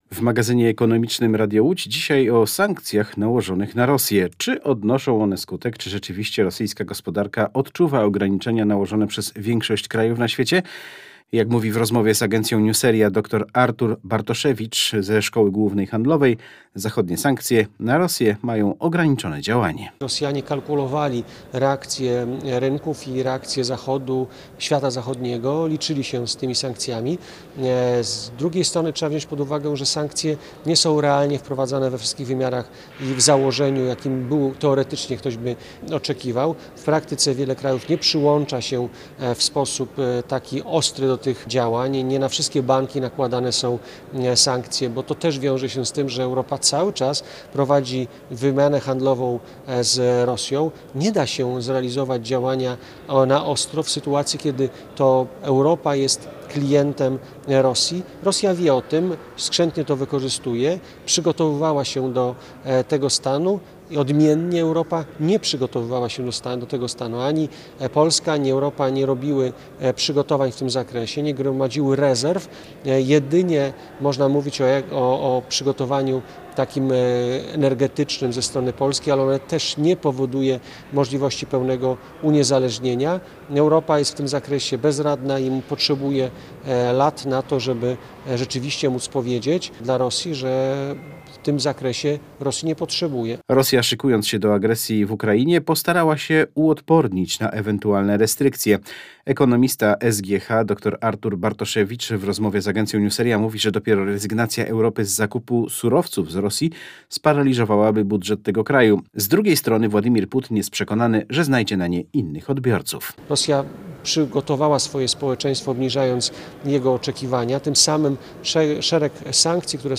W magazynie ekonomicznym Radia Łódź dzisiaj o sankcjach nałożonych na Rosję.